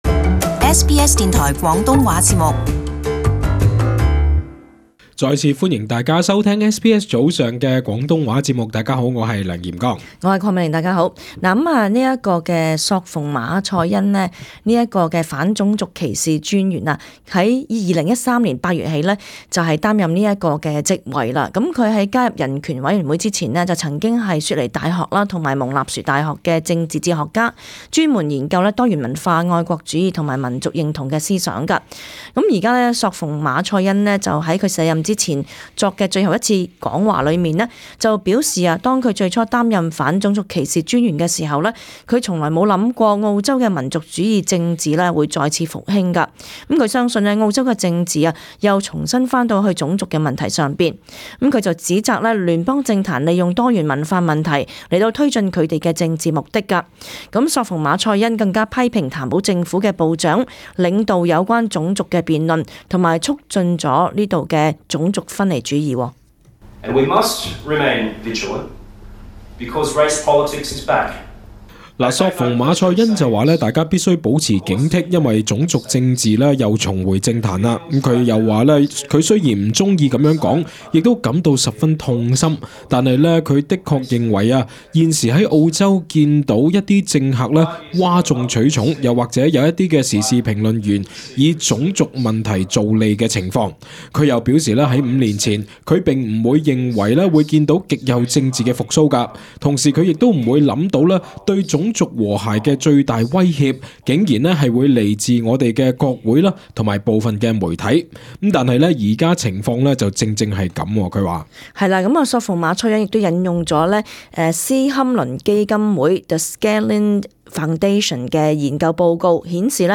【時事報導】政界及傳媒被指利用種族議題獲益